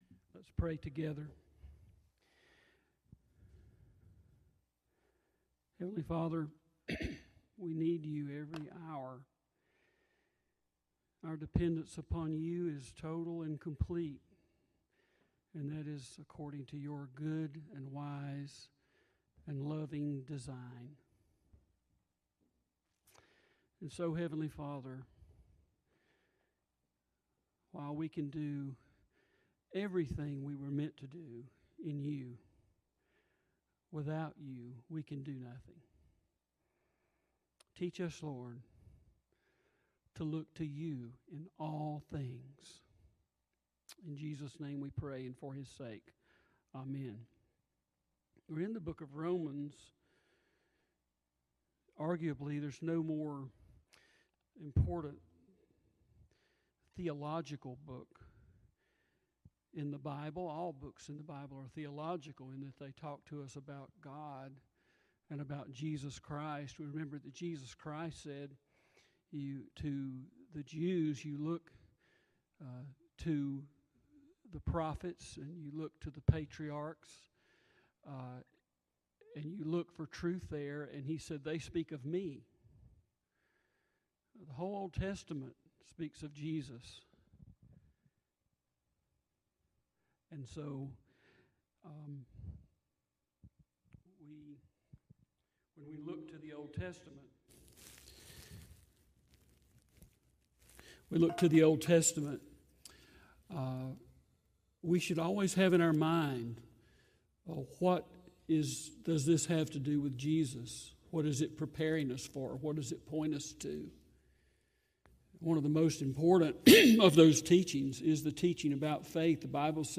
Sermons | Smoke Rise Baptist Church